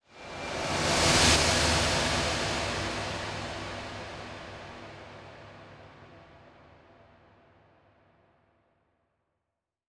VTS1 Incast Kit Sound FX
VTS1 Incast Kit 140BPM ReverseFx.wav